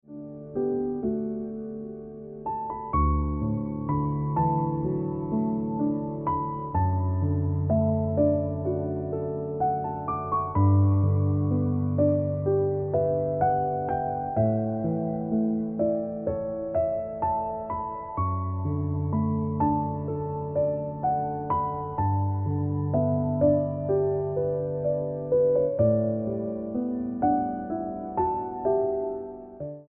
Best Ringtones, Piano Music Ringtone
Piano Music For Android and iPhone mobiles.